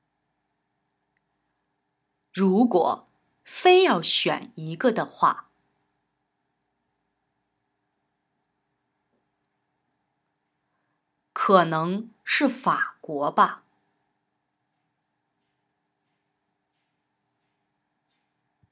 Wir sprechen in Kooperation mit dem Konfuzius Institut Magazin regelmäßig mit Passanten auf Chinas Straßen über spannende Alltagsfragen und bereiten ihre Antworten exklusiv für euch als Wundertüten-Abonnenten in unserer Rubrik NIU NACHGEFRAGT 牛采访 auf, mit zweisprachigen Lesetexten, nützlichen Vokabeln, einer nachgesprochenen Hörversion und aktiven Sprechübungen, in denen ihr nützliche Satzmuster selbst noch einmal aktiv nachsprechen könnt.
Trainiere deine Sprechmuskeln: